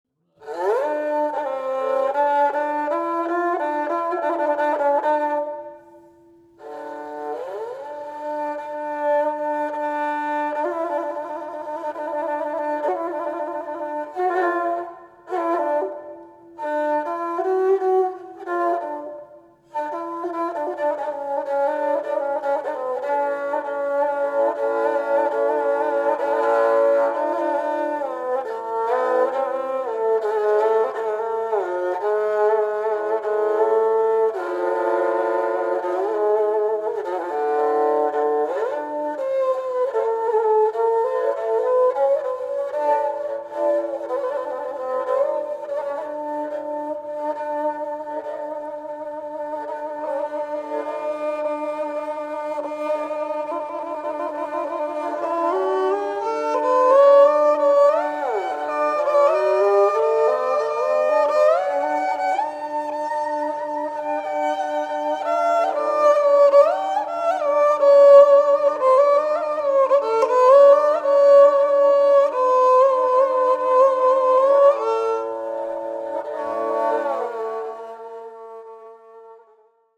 Genre: Turkish & Ottoman Classical.
Joint Improvisation 4:57
Recorded on October 5 & 6,1999 in Walnut Creek, California